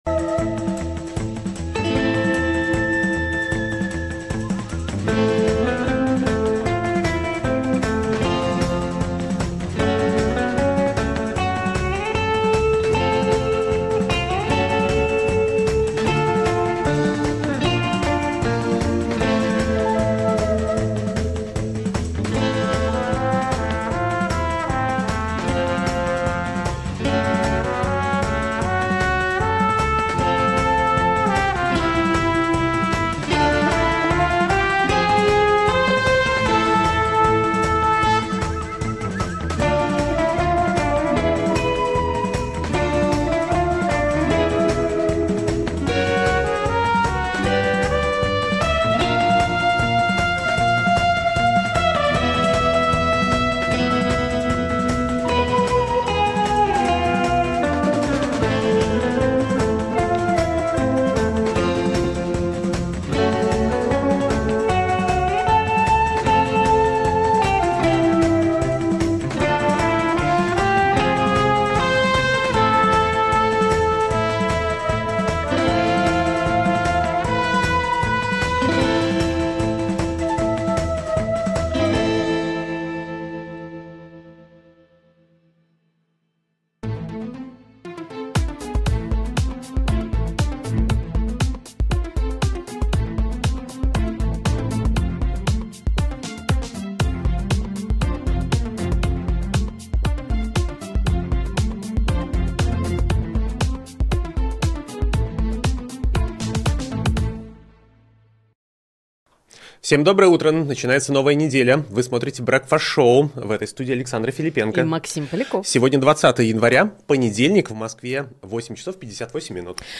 Обсудим с экспертами в прямом эфире все главные новости.